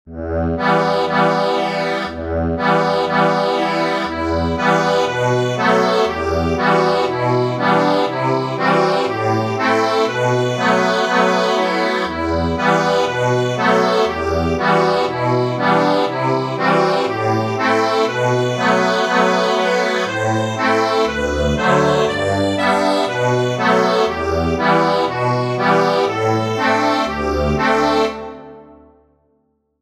Instrument:  accordion